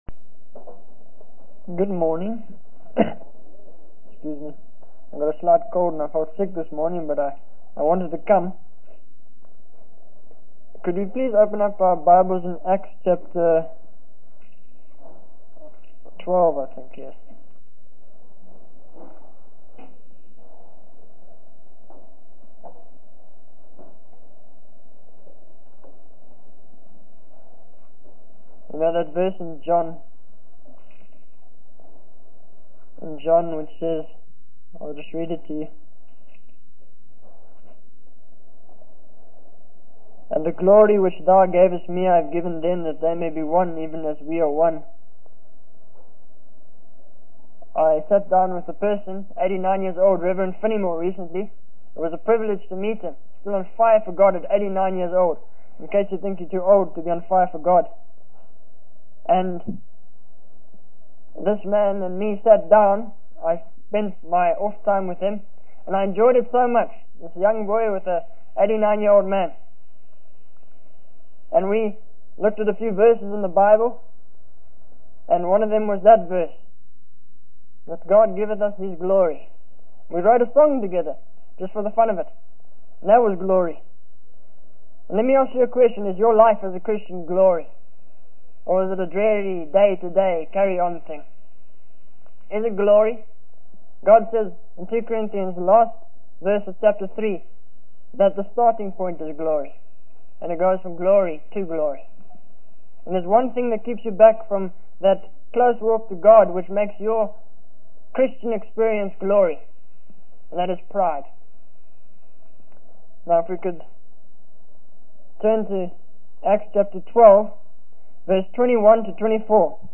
In this sermon, the preacher begins by praying for God's guidance and acknowledging that he is nothing without God's words. He then discusses the dangers of pride in one's Christian experience and the impact it can have on one's power with others and with God.